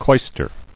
[clois·ter]